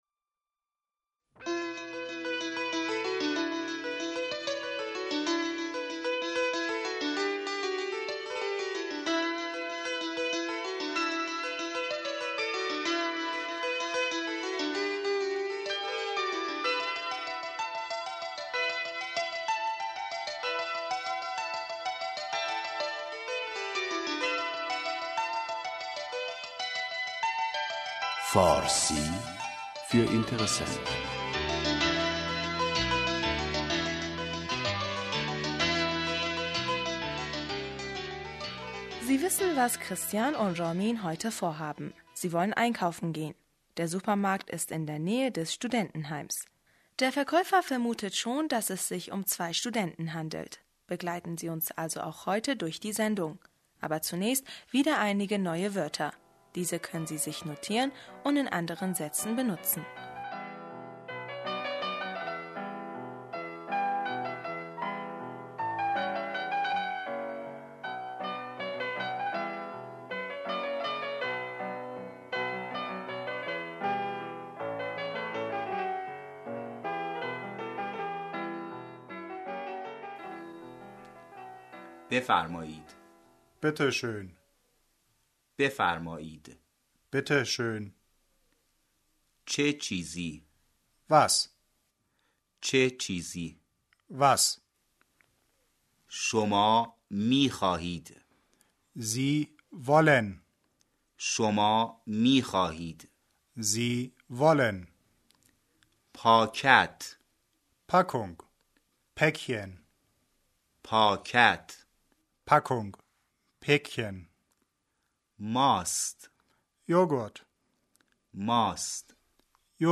(Jeweils zweimal) Bitte schön befarmâid بفرمائید Was? tsche tschizi?